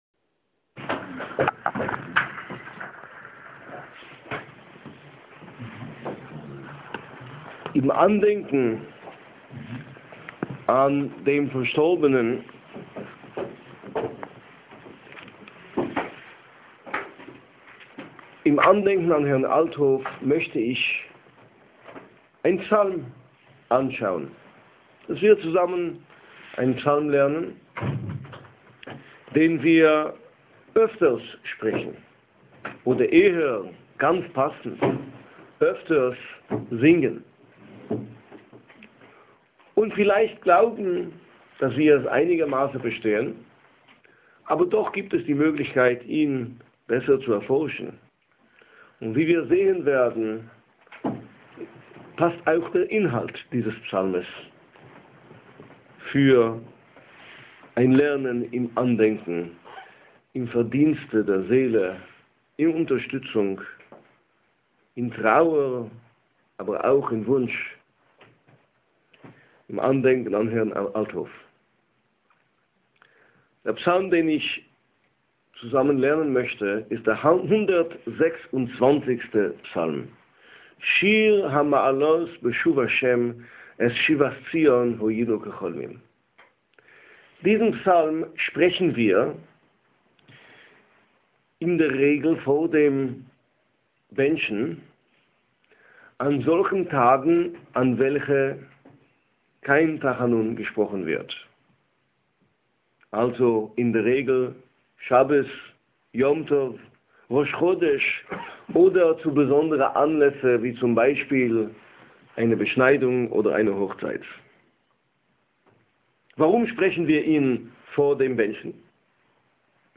Audio-Schiur